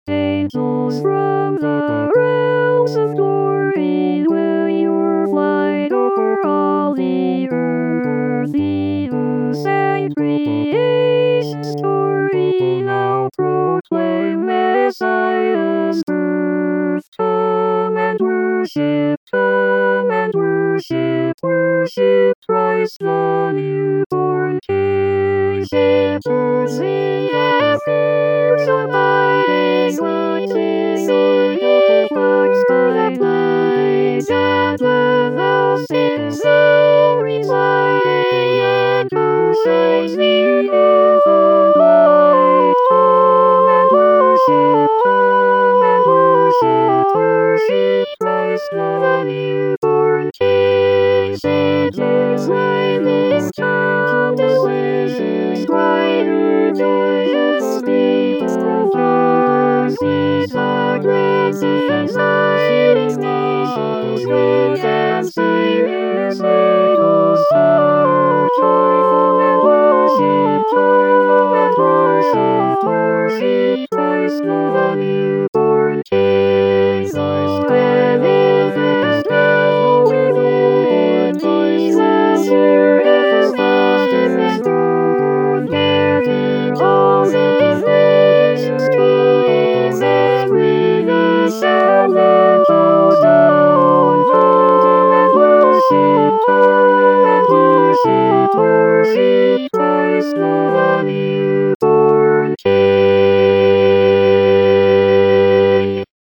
So, then, folks, this was the miracle of the Christmas Carols -- there were 4 people singing 3 different Christmas carols, and somehow it all worked!
Audio file of a synthesized quartet singing "The Miracle of the Christmas Carols"
MP3 format; better with headphones for full stereo effect!